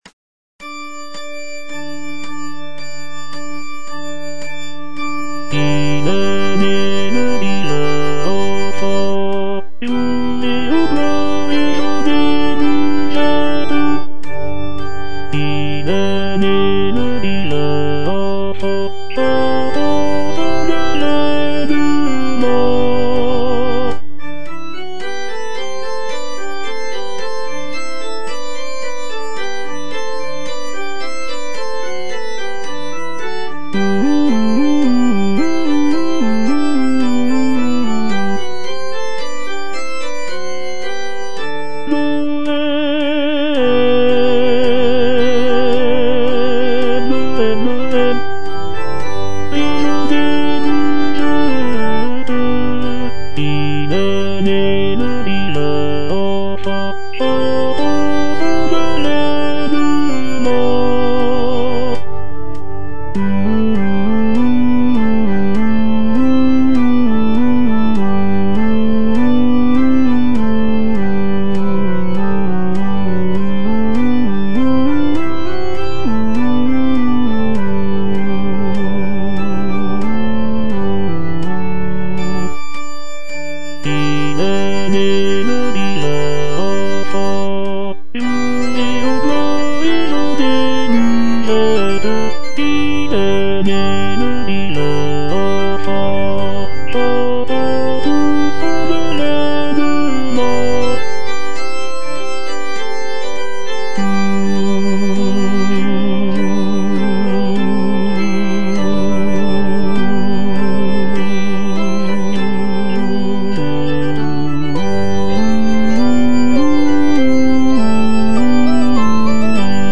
- IL EST NÉ, LE DIVIN ENFANT Tenor II (Voice with metronome) Ads stop:
It is a joyful and lively song that celebrates the birth of Jesus Christ.